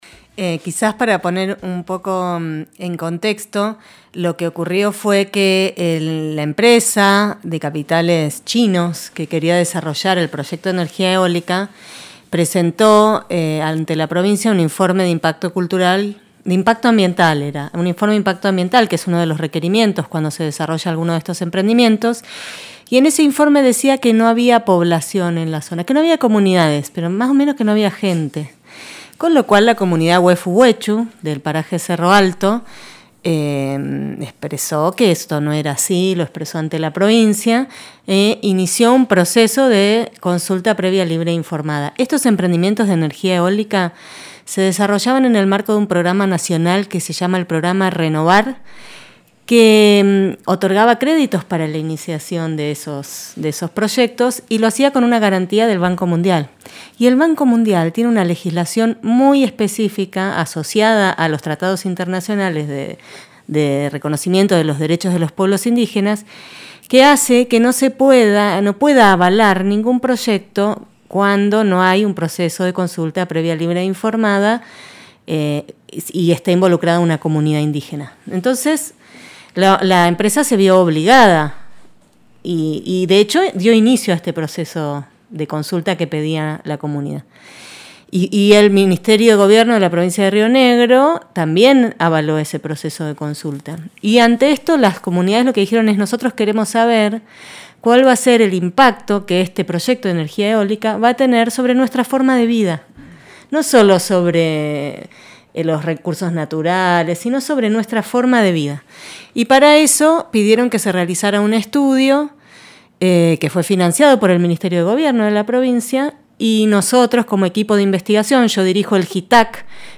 En diálogo con Nosotres les Otres habló sobre el contexto del proceso de consulta previa, sobre el trabajo realizado, de la presentacón en la escuela de Cerro Alto y señaló lo que se destaca del estudio.